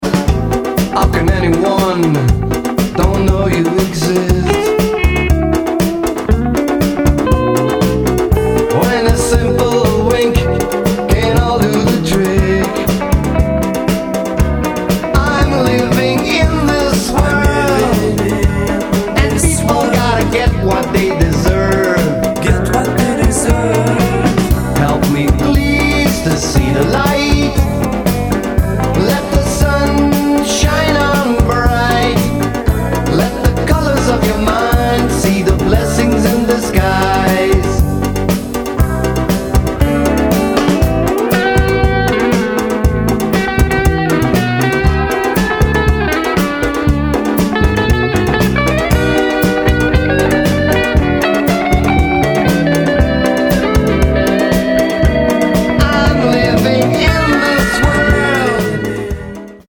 Four brothers and a cousin who play music.